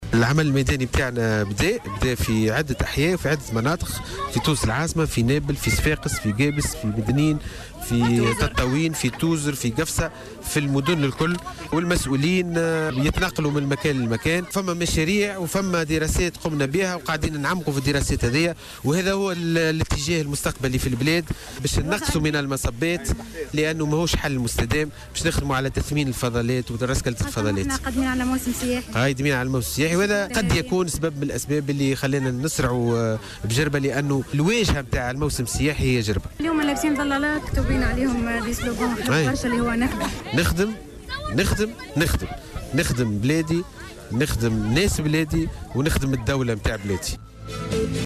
أكدت وزيرة السياحة،أمال كربول اليوم،السبت في تصريح ل"جوهرة أف أم" على هامش زيارة عمل لولاية مدنين لمتابعة ضمان سير خطة التصرف في النفايات بجزيرة جربة أن الوضع البيئي عامل مهم لجلب السياح والمستثمرين الى تونس